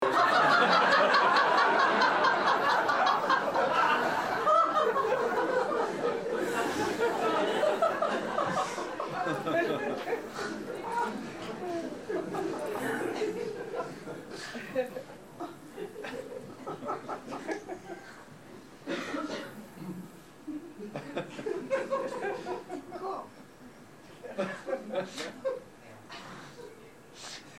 Laughter Sound Button: Unblocked Meme Soundboard
Laughter Sound Effects